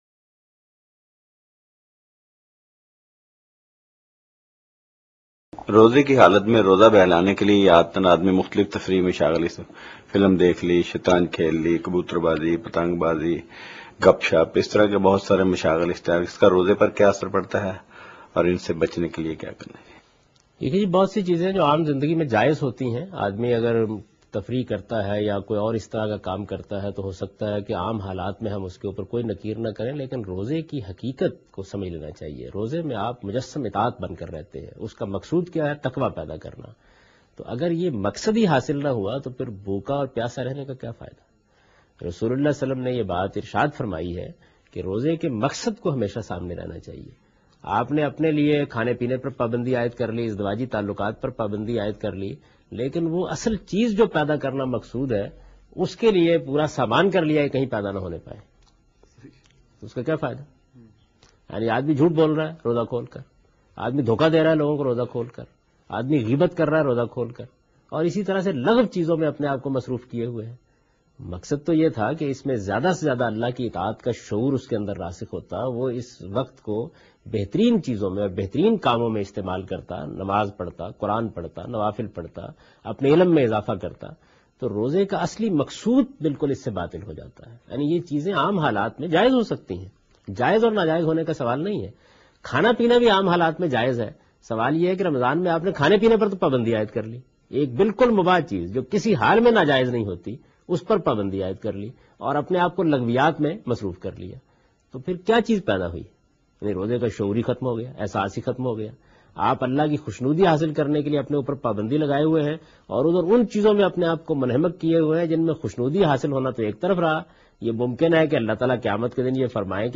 Category: Reflections / Questions_Answers /
جاوید احمد غامدی روزے کے دوران تفریح کی حیثیت کے متعلق بیان کر رہے ہیں